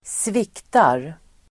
Uttal: [²sv'ik:tar]